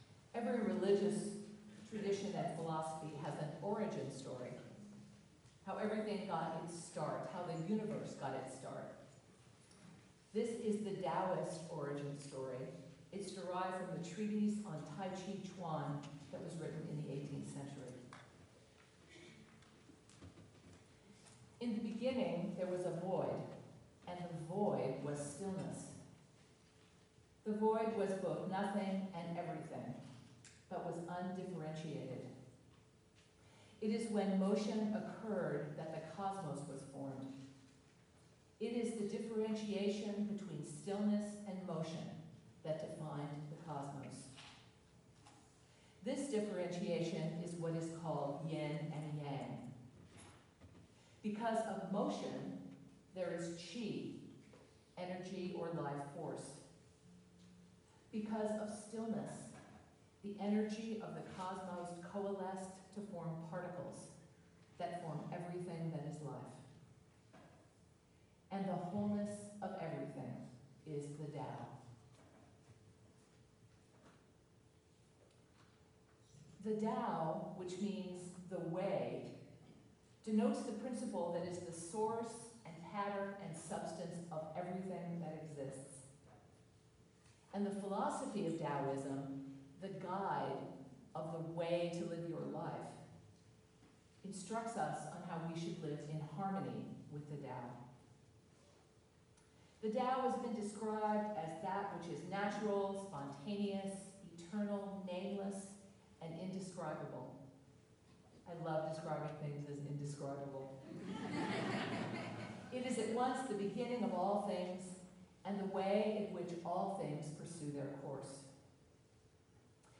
Sermon-The-Tao.mp3